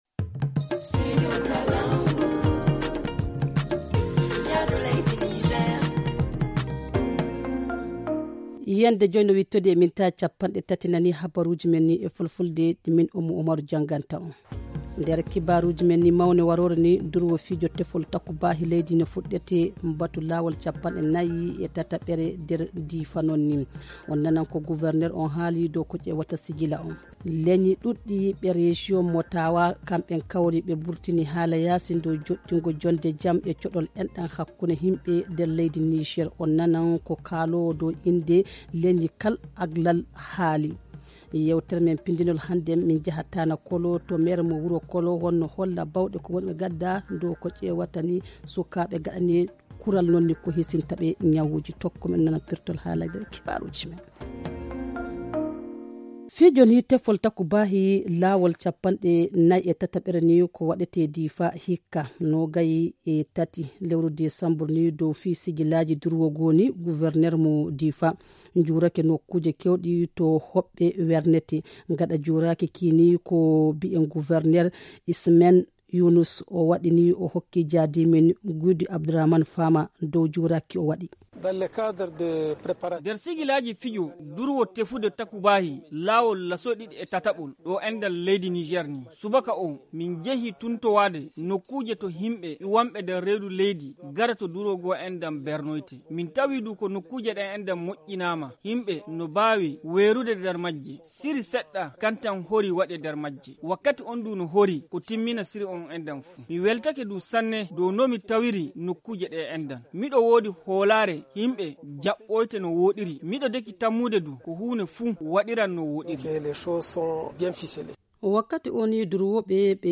Le journal du 20 décembre 2022 - Studio Kalangou - Au rythme du Niger